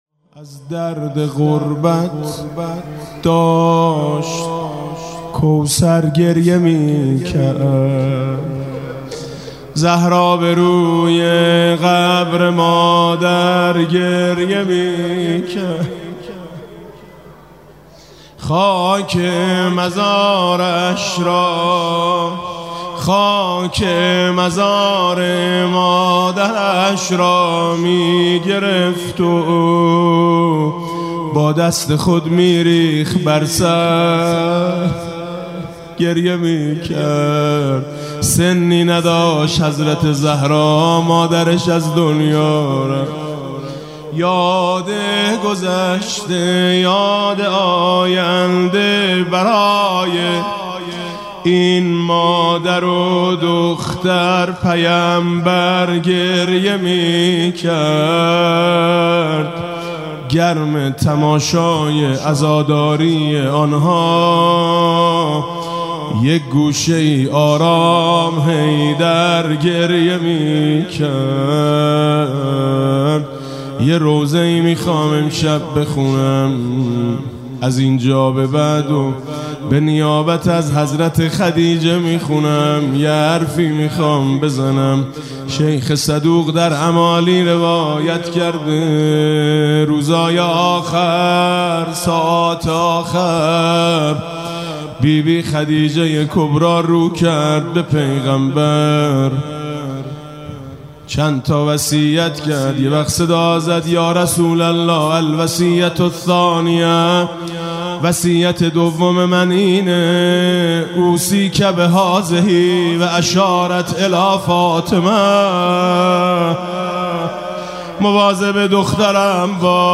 زهرا به روی قبر مادر گریه میکرد (روضه